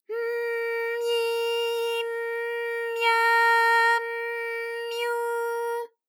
ALYS-DB-001-JPN - First Japanese UTAU vocal library of ALYS.
my_m_myi_m_mya_m_myu.wav